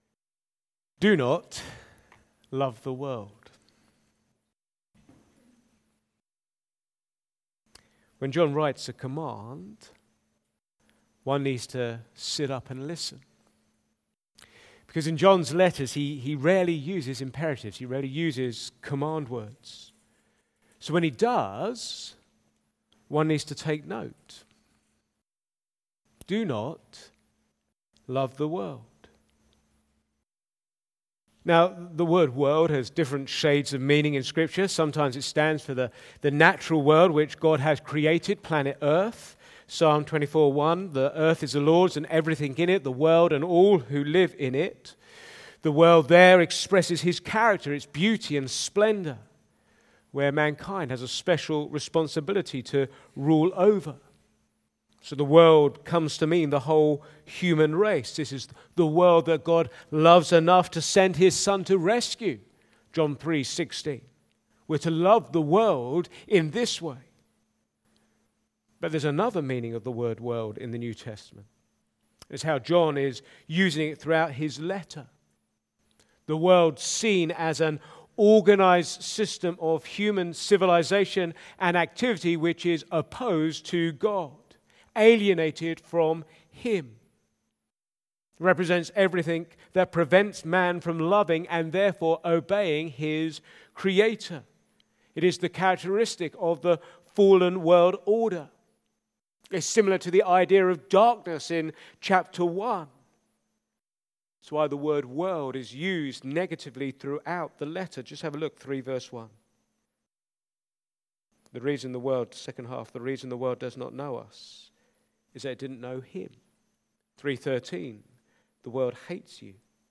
An independent evangelical church
Back to Sermons Loving the world